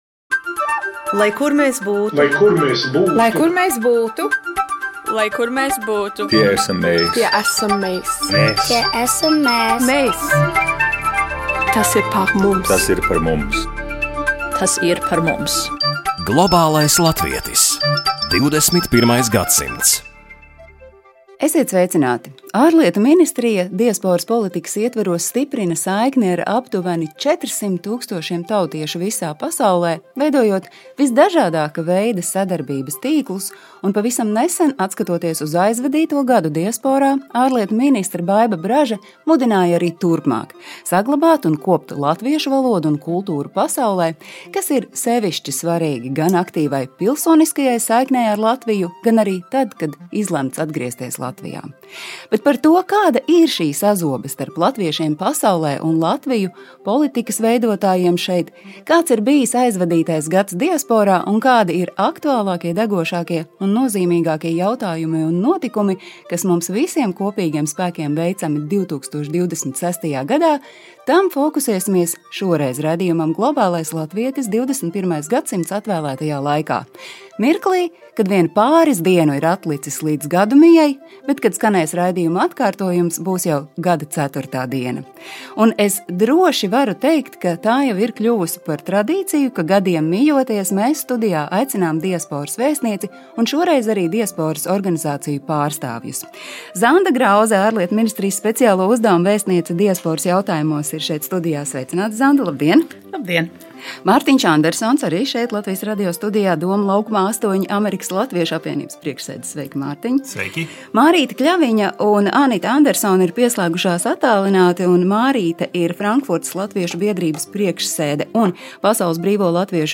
Tā jau ir kļuvusi par tradīciju, ka, gadiem mijoties, studijā aicinām diasporas vēstnieci un šoreiz arī diasporas organizāciju pārstāvjus. Zanda Grauze, Ārlietu ministrijas speciālo uzdevumu vēstniece diasporas jautājumos, ir studijā.